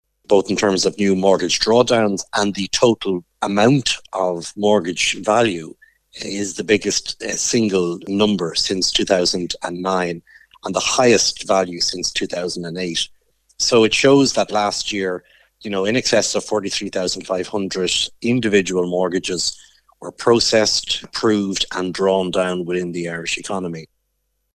Chief Executive of the BPFI, Brian Hayes, says the numbers are significant: